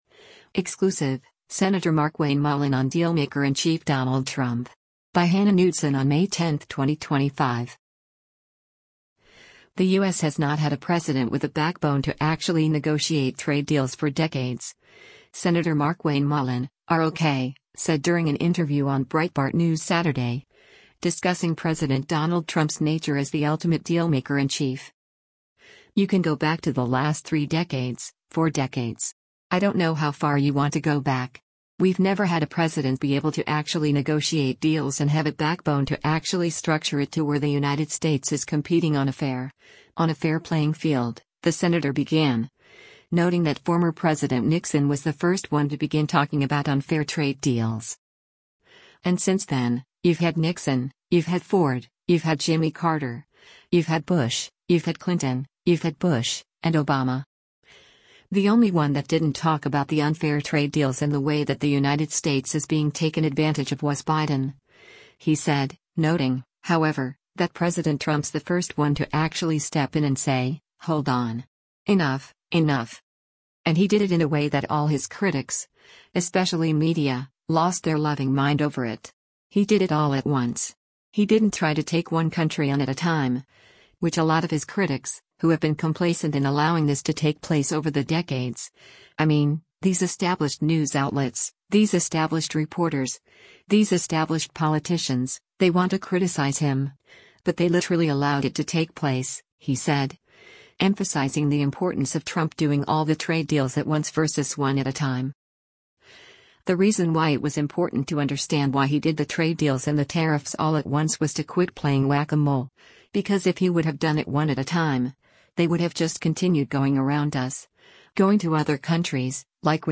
The U.S. has not had a president with a backbone to actually negotiate trade deals for “decades,” Sen. Markwayne Mullin (R-OK) said during an interview on Breitbart News Saturday, discussing President Donald Trump’s nature as the ultimate dealmaker-in-chief.